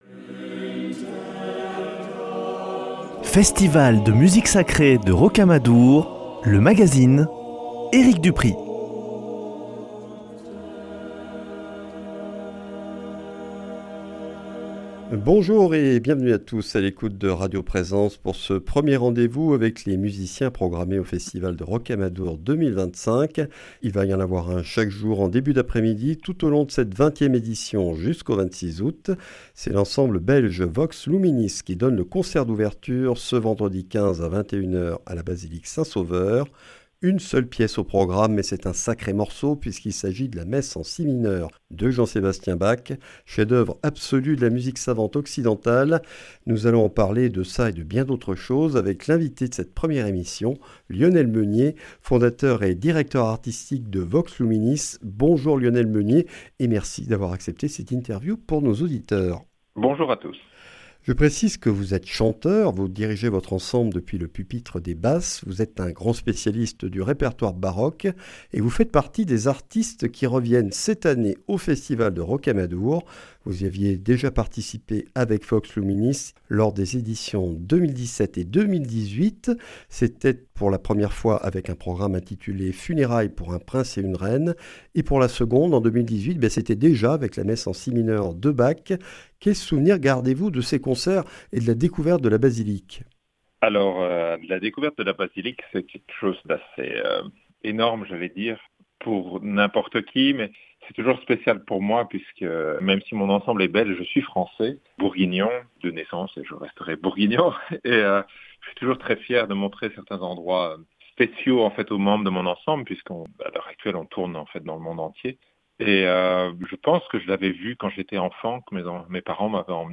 Entretien avec un baroqueux passionné et érudit qui fourmille de projets.